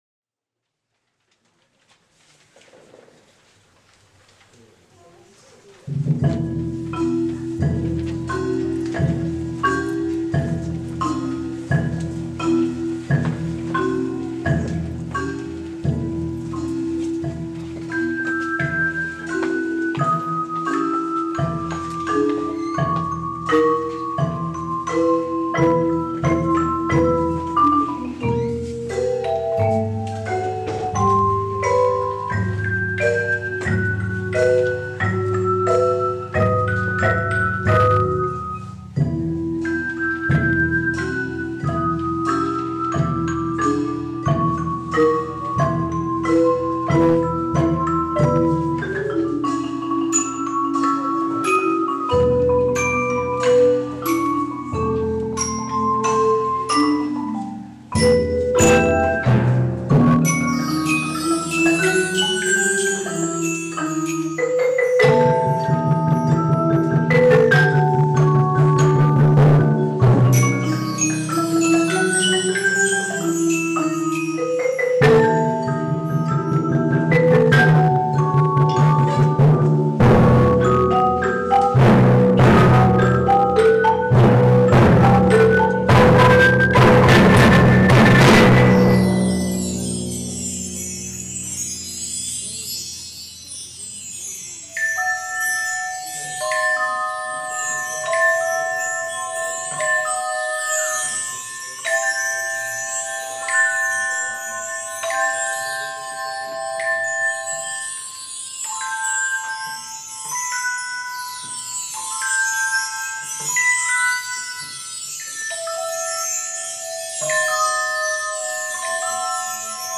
con el ensamble de idiofonos